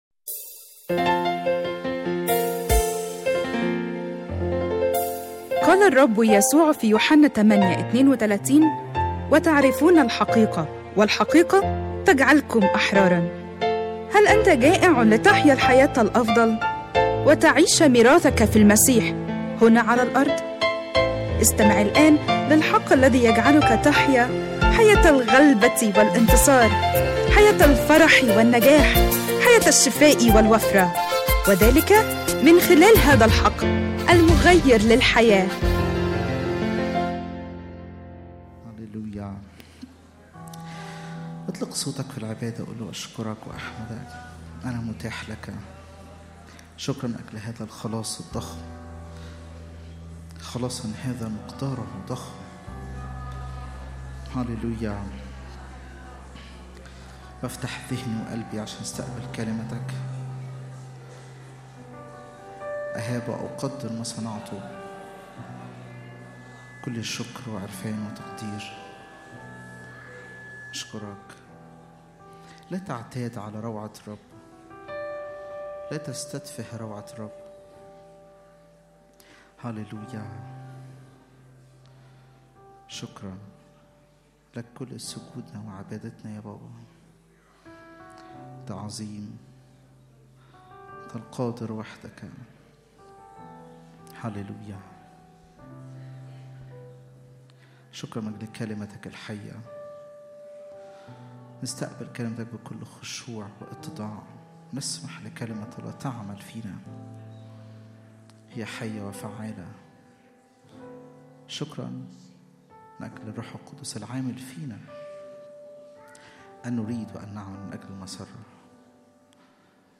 اجتماع الثلاثاء